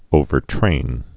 (ōvər-trān)